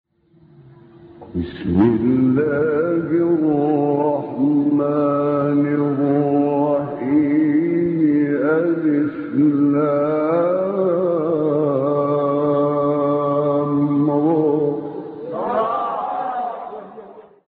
12 فراز در مقام «بیات حسینی» از شیخ محمد عمران
گروه فعالیت‌های قرآنی: فرازهایی از مقام بیات حسینی با صوت محمد عمران ارائه می‌شود.
به گزارش خبرگزاری بین المللی قرآن (ایکنا)، دوازده فراز در مقام بیات حسینی از محمد عمران، قاری برجسته مصری در کانال تلگرامی این قاری مصری منتشر شده است که در زیر ارائه می‌شود.